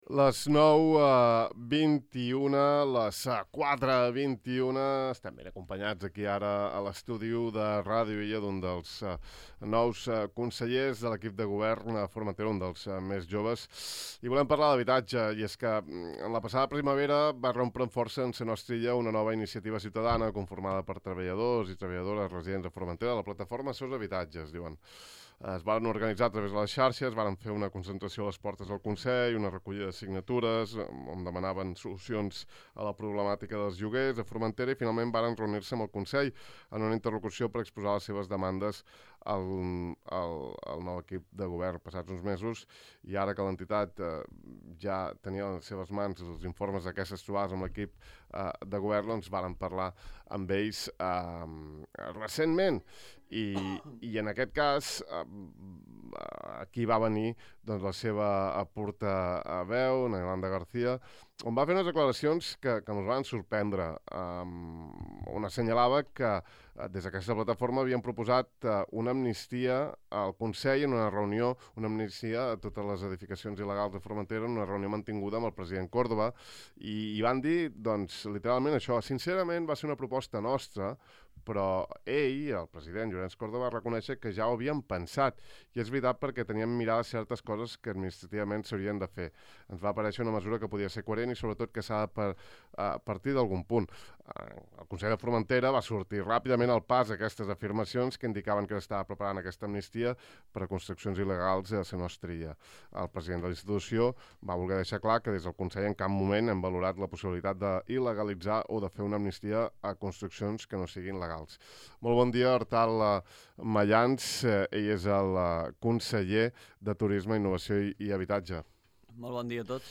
I aquest matí ha passat pel De far a far el conseller del ram, Artal Mayans, per donar-nos més detalls d’aquesta quantia, que podria semblar irrisòria si es té en compte l’elevat pressupost d’aquests ajuts.